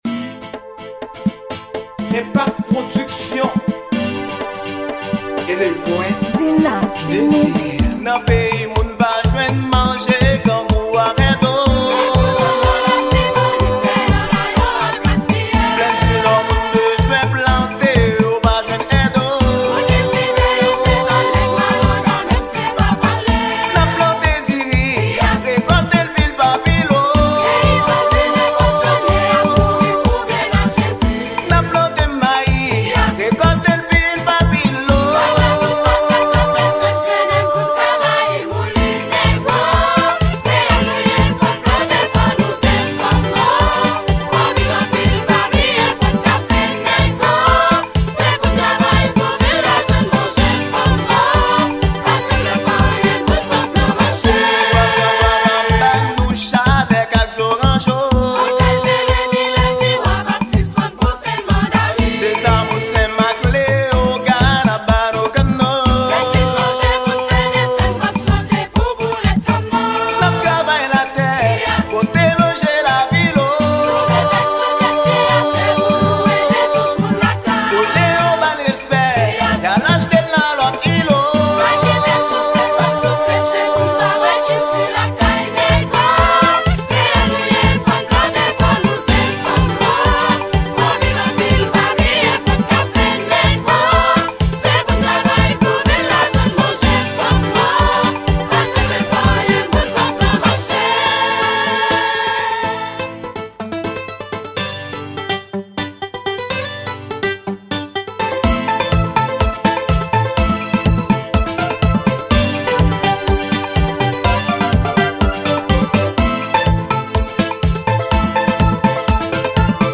Genre: WORLD.